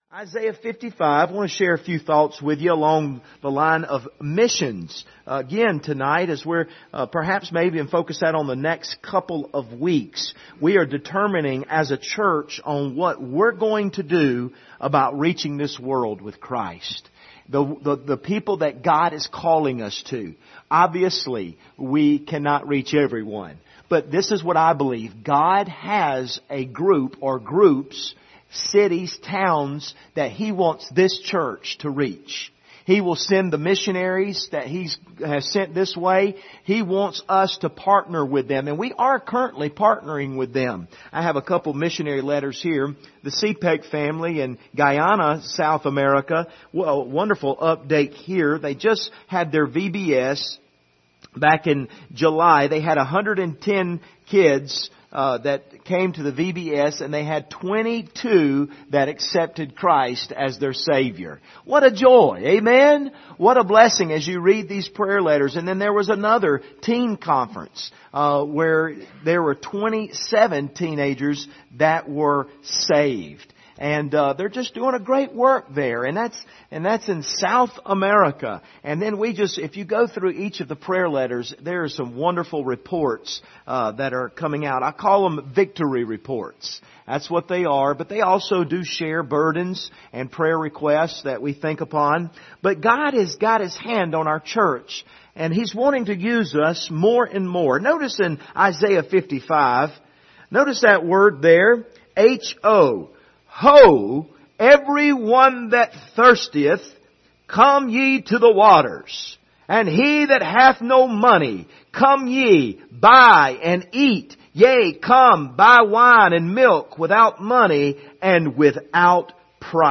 Isaiah 55:1-13 Service Type: Sunday Evening Topics